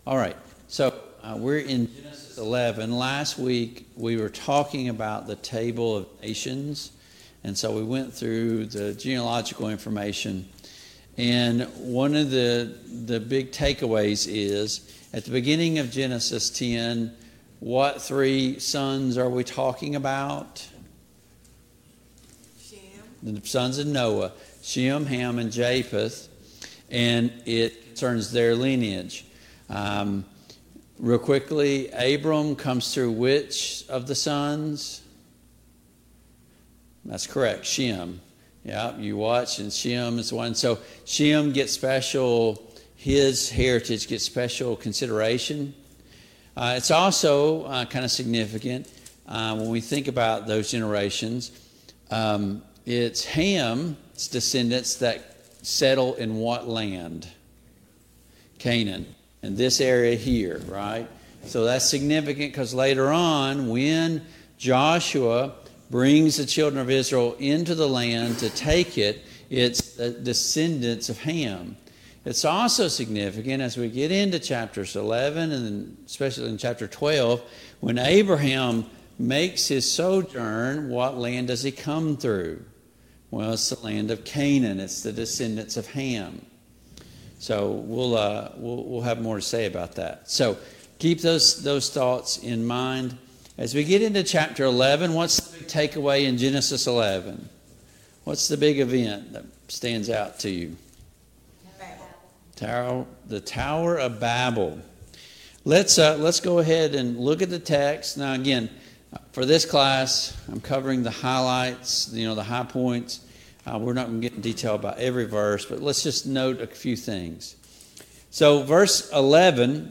Genesis 11 Service Type: Family Bible Hour Topics: The Tower of Babel « How do we pass on the Faith to our children? 1.